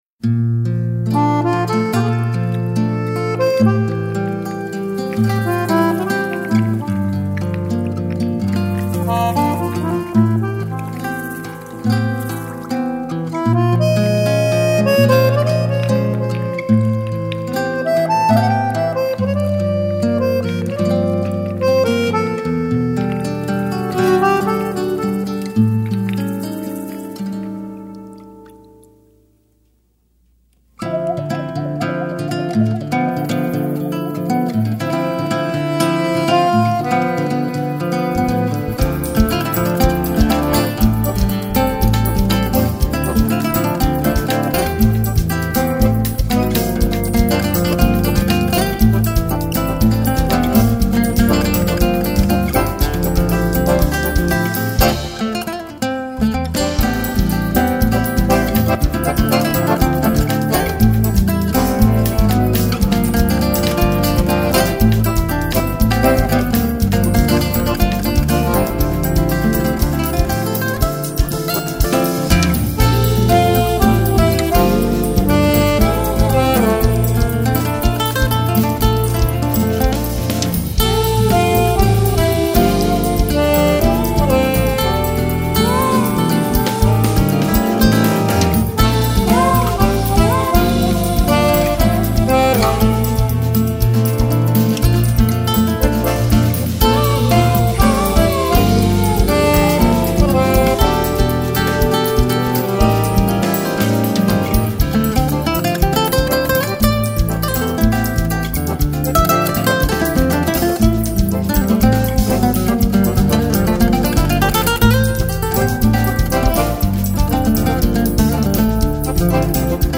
1116   03:59:00   Faixa:     Instrumental
Violao Acústico 6, Triângulo
Acoordeon
Baixo Elétrico 6
Bateria